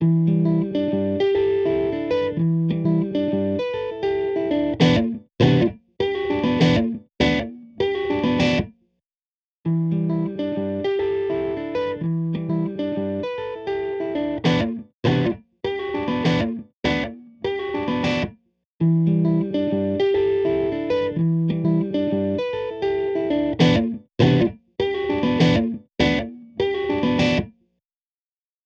EQ65 | Electric Guitar | Preset: Pick Attack Removal
EQ65-Pick-Attack-Removal.mp3